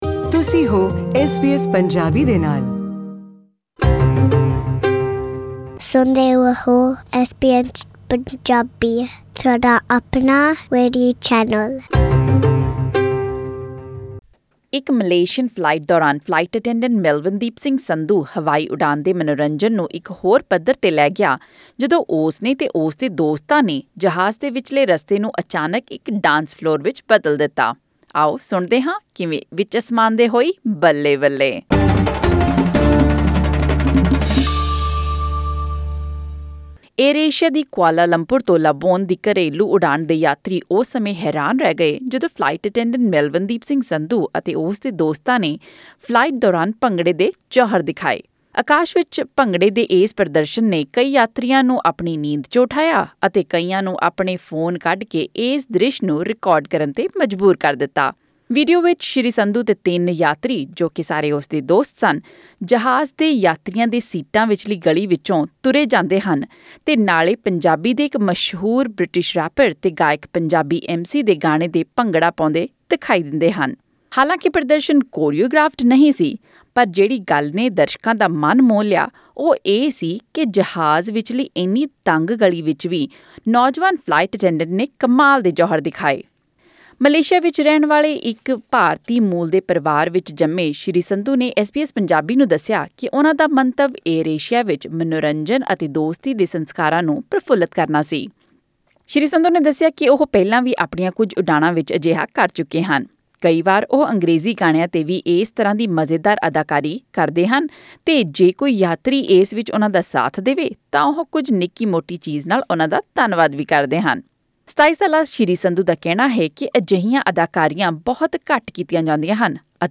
ਆਡੀਓ ਰਿਪੋਰਟ